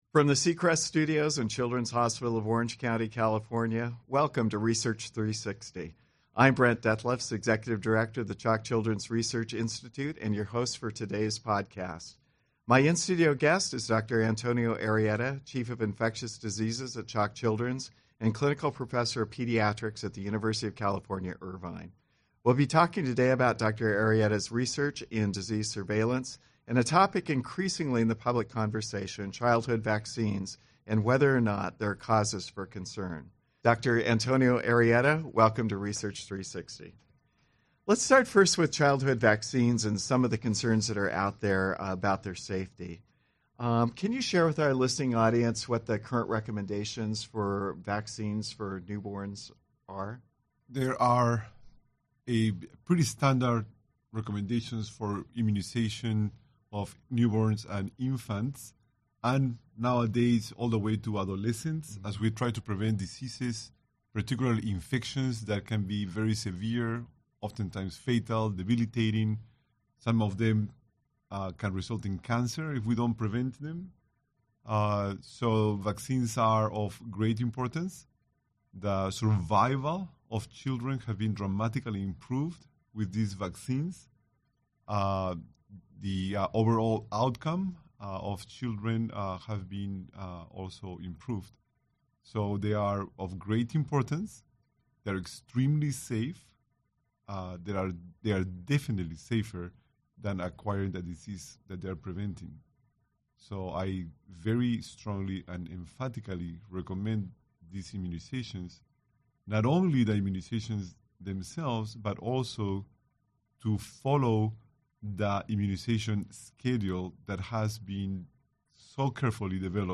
Research 360° features interviews with scientists, physicians, educators, scientific news-and policy-makers to provide the listening audience with context